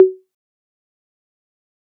Perc (Pharrellish).wav